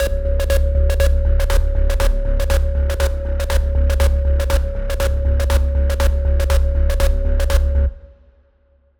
In the sound effect part, I chose some high-frequency sounds to enhance the feeling of anger.
SlowAnger.wav